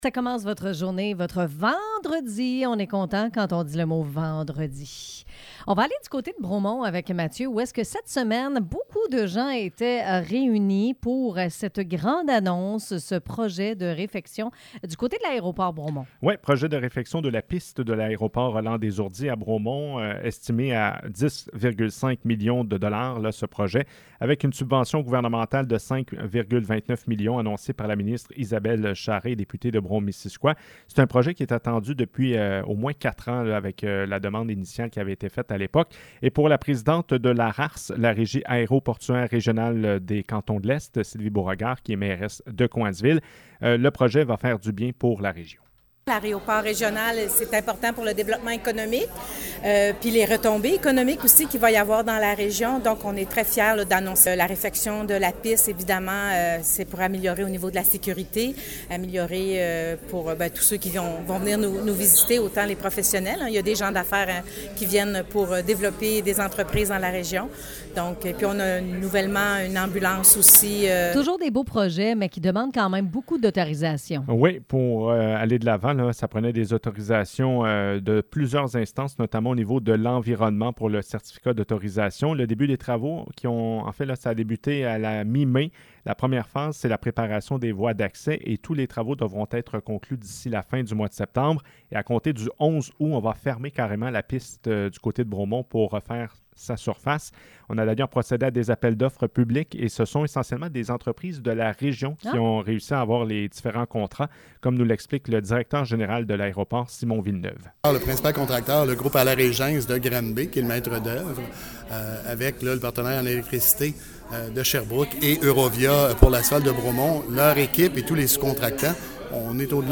Nouvelles